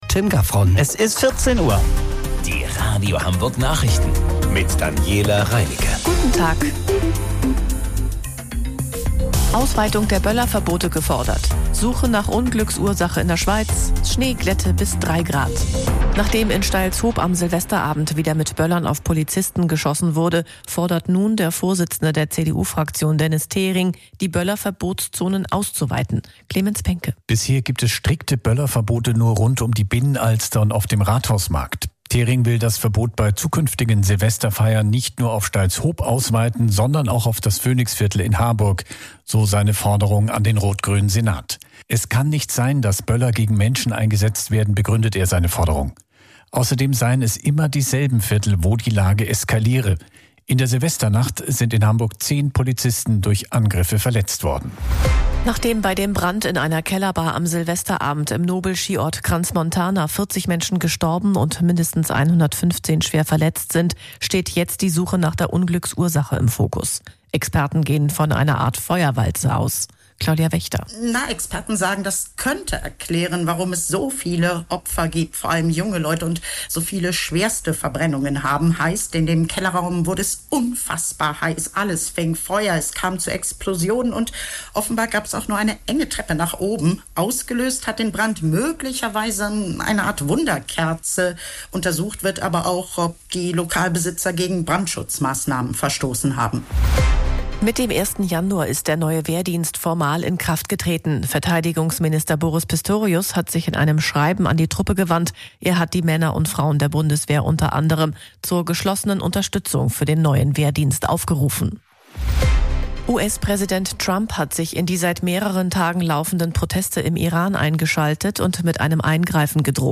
Radio Hamburg Nachrichten vom 02.01.2026 um 14 Uhr